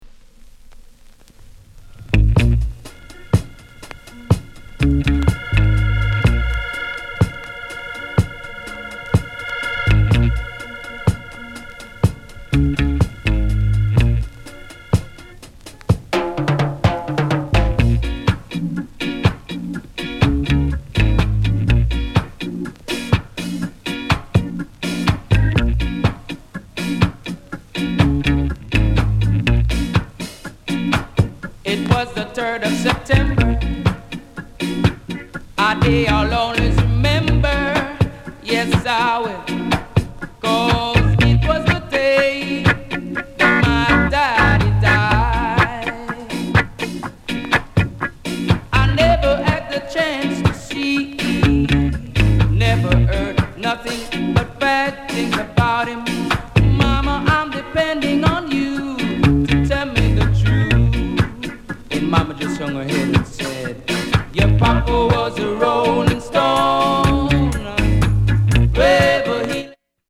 FUNKY REGGAE